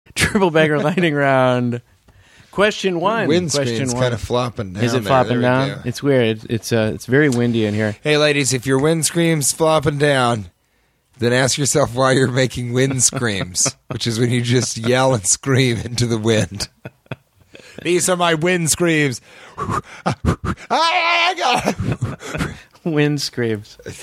Yells and screams uttered into the wind